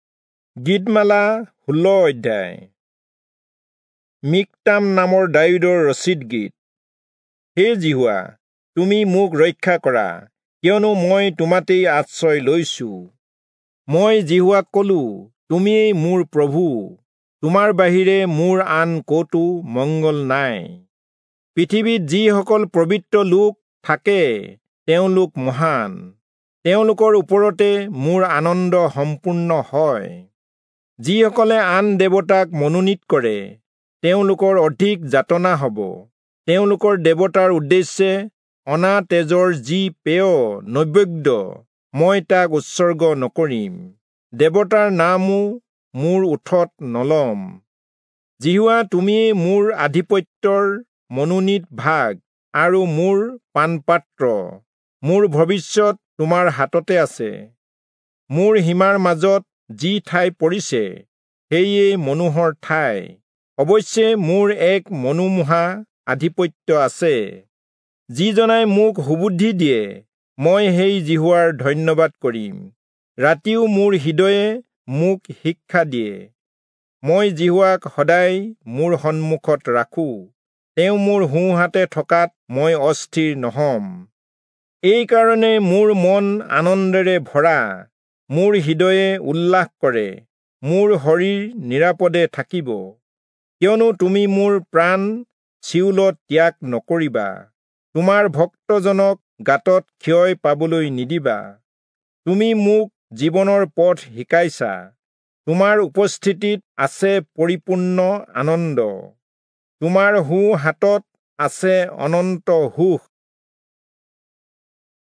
Assamese Audio Bible - Psalms 141 in Ncv bible version